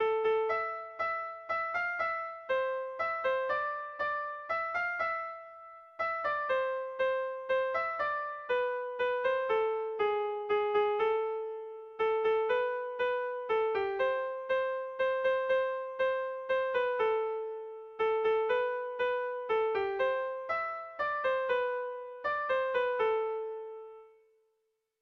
Sentimenduzkoa
Zortziko ertaina (hg) / Lau puntuko ertaina (ip)
ABDE